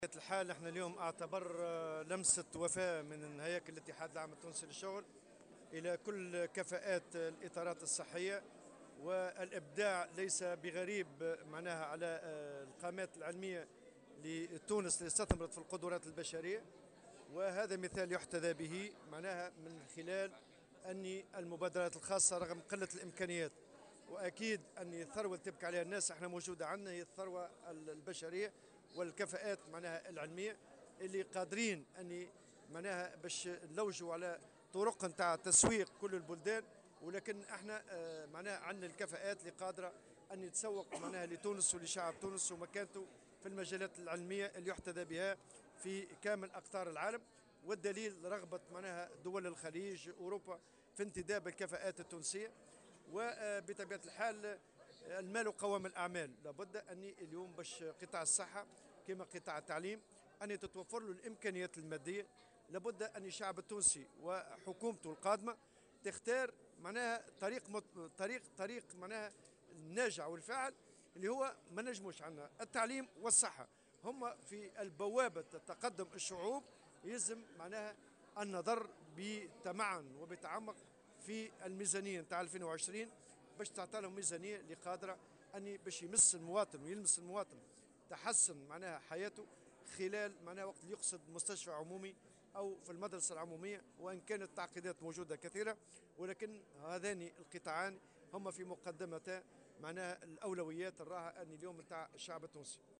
وأوضح في تصريح اليوم على هامش لقاء تكريمي لمهني القطاع الصحي، أن الكفاءات التونسية مشهود لها في جميع المجالات والدليل على ذلك تواصل عمليات استقطابها للعمل في الخارج وبالأخص في البلدان الخليجية.